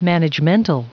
Prononciation du mot managemental en anglais (fichier audio)
Prononciation du mot : managemental